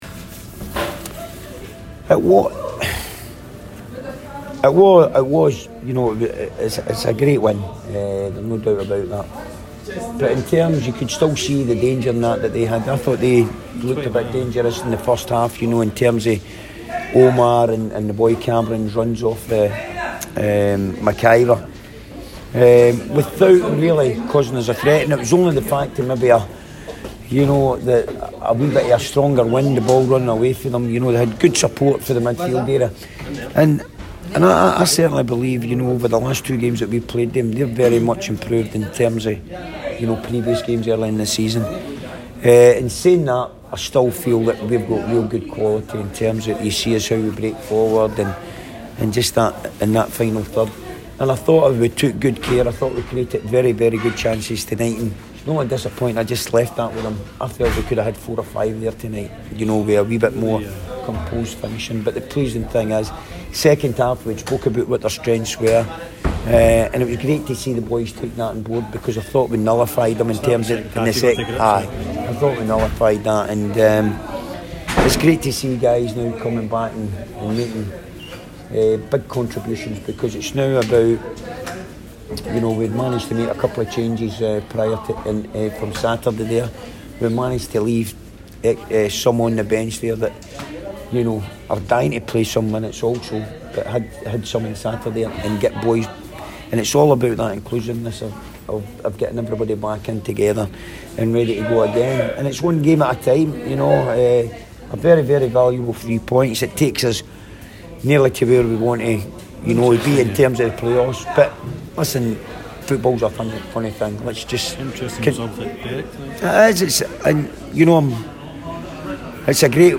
press conference after the Ladbrokes League 2 match.